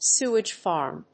アクセントséwage fàrm